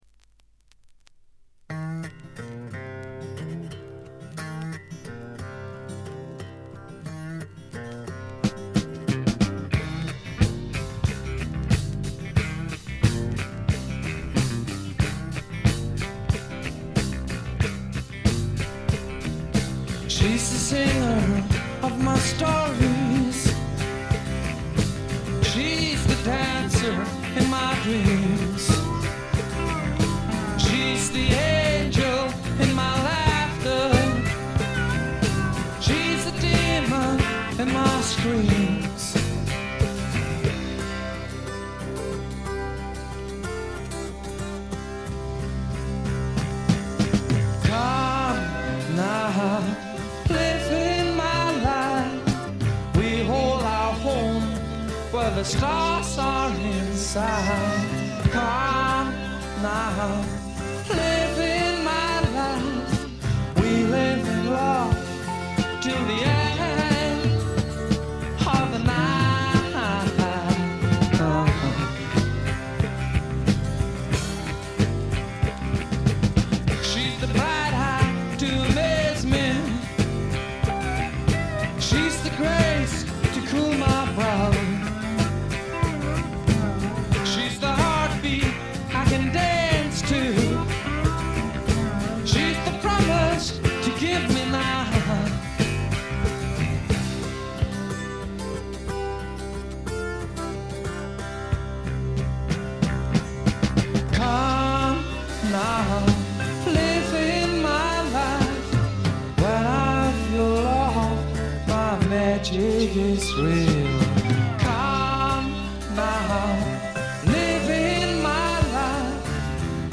静音部で軽微なチリプチ。
ドイツにひっそりと残るフォーク・ロック、サイケ／アシッド・フォークの名盤。
試聴曲は現品からの取り込み音源です。